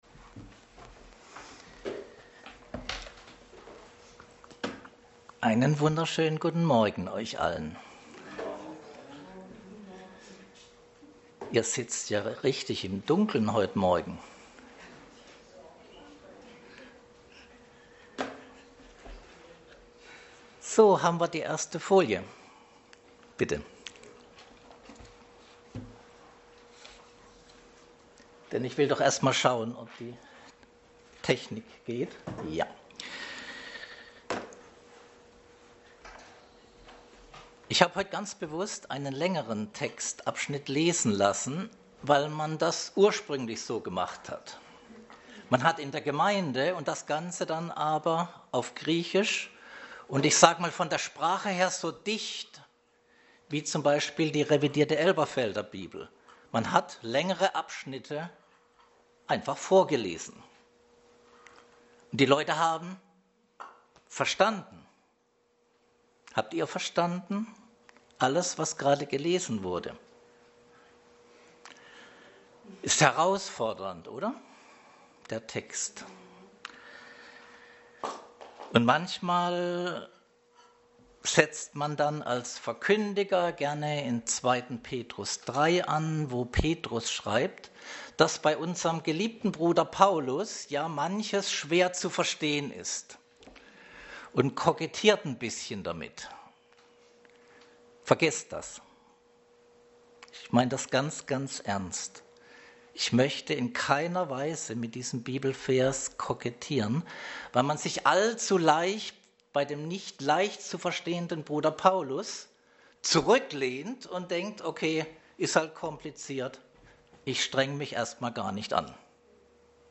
Passage: Römer 8,1-14 Dienstart: Predigt